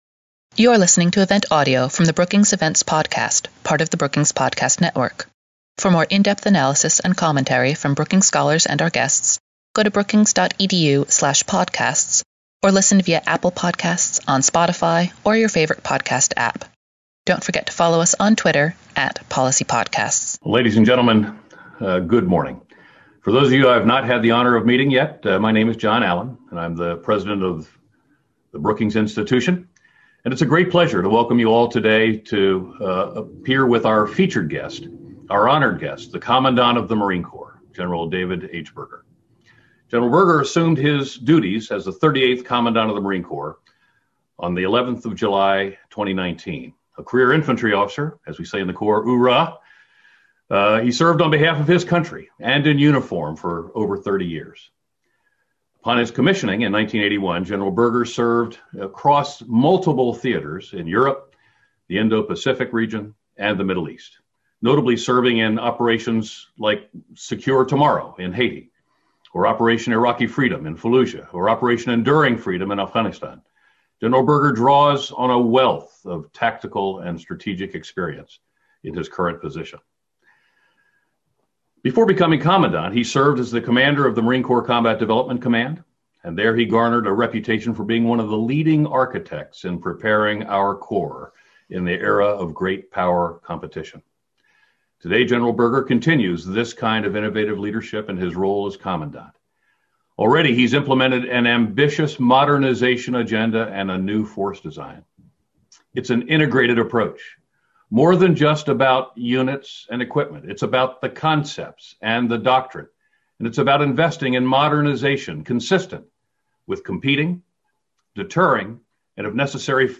On May 18, Foreign Policy at Brookings hosted Commandant of the Marine Corps General David H. Berger to discuss Marine Corps modernization, the budgetary environment, and the challenges of great power competition. Questions from the audience followed the conversation.